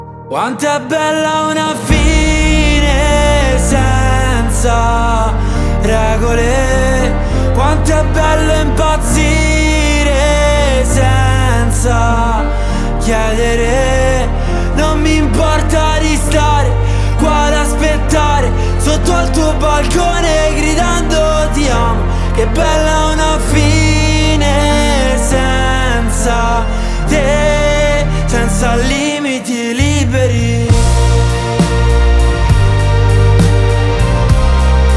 Hip-Hop Rap Pop
Жанр: Хип-Хоп / Рэп / Поп музыка